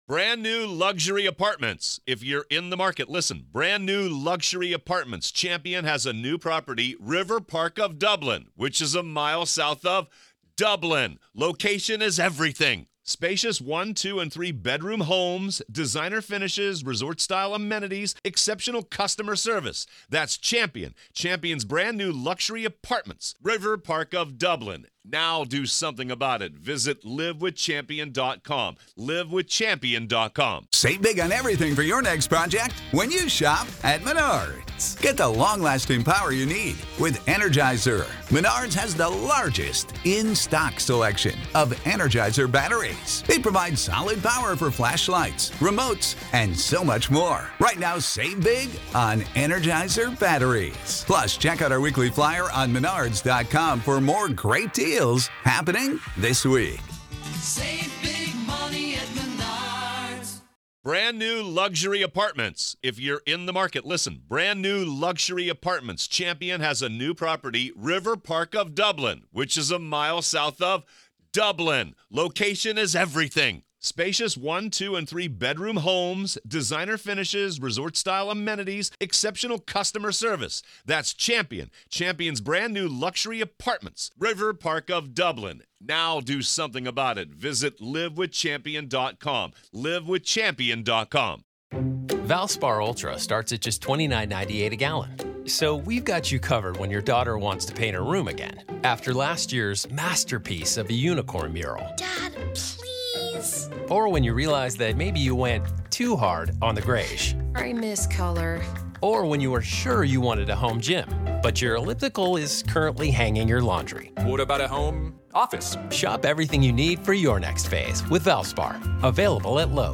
This significant interview took place at the family's Moselle property in South Carolina, a location central to the ongoing investigation.